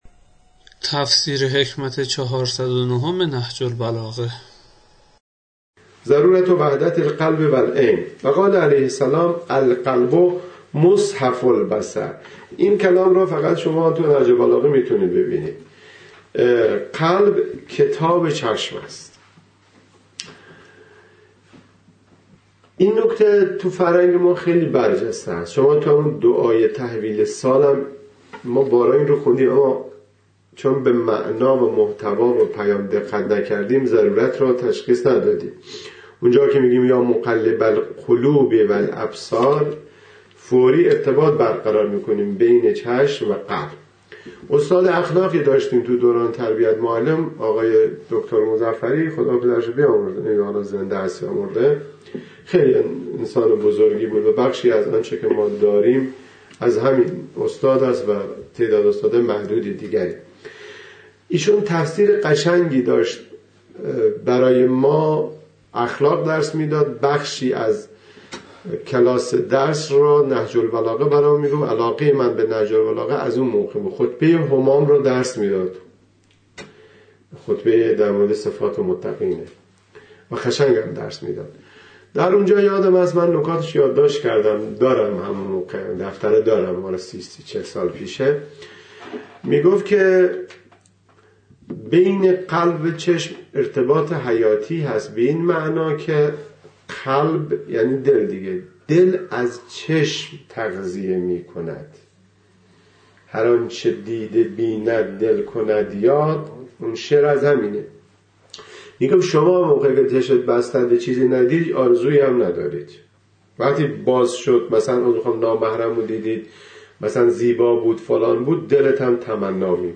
تفسیر